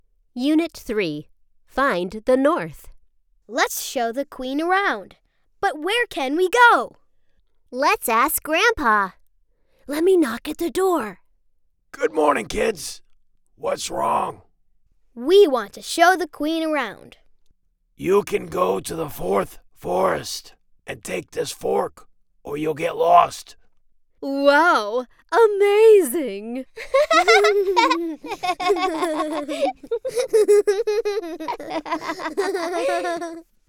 英语样音试听下载
英语配音员（女1,多角色） 英语配音员（女2） 英语配音员（女3）